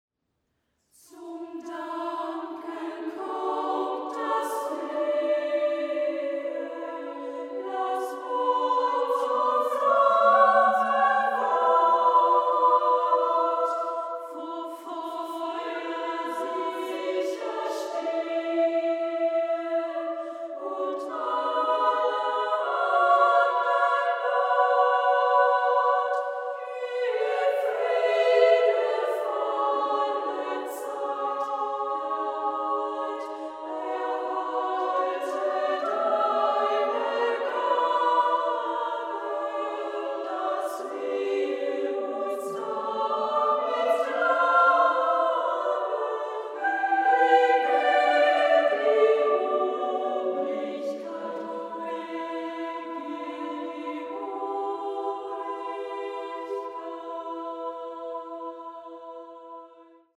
Werke für Frauen- und Kinderchor und Sololieder